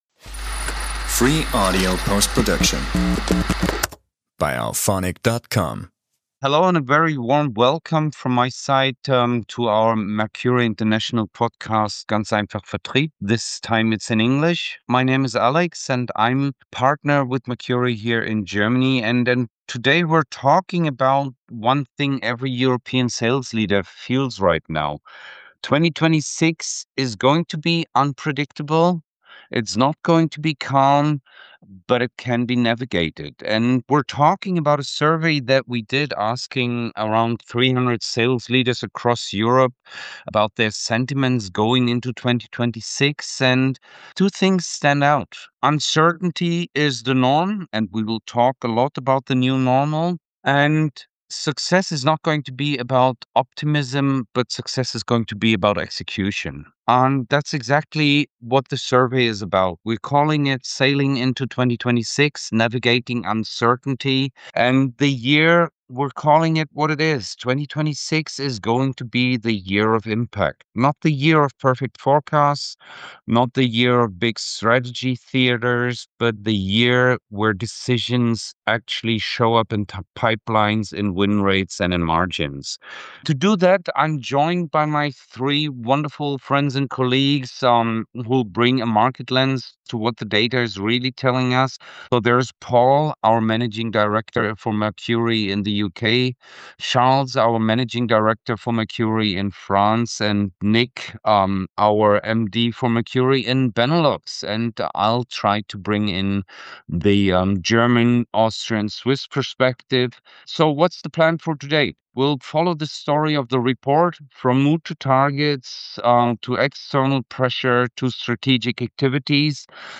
In this special episode, four of our Sales Excellence experts from across Europe unpack what 300 European sales leaders really think about 2026.